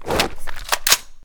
combat / weapons / default_shootable / draw1.ogg
draw1.ogg